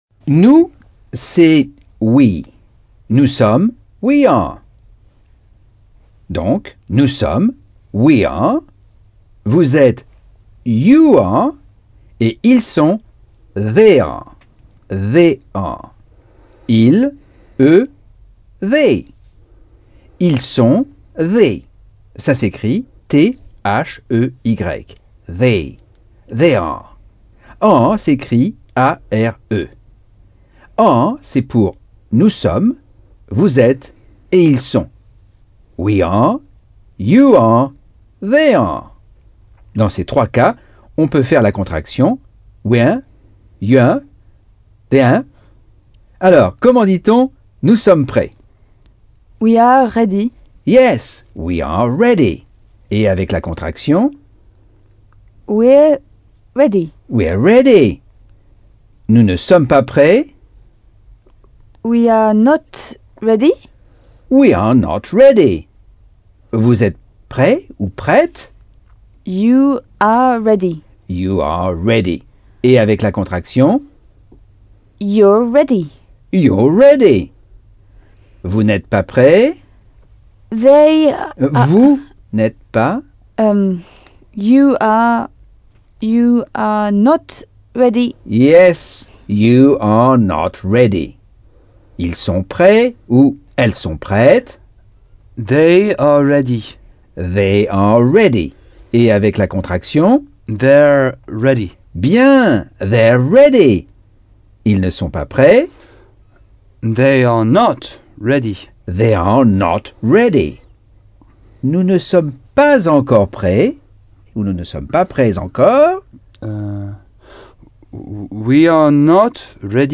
Leçon 5 - Cours audio Anglais par Michel Thomas